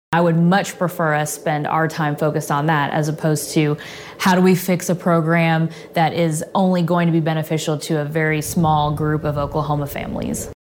CLICK HERE to listen to commentary from Democrat Cyndi Munson.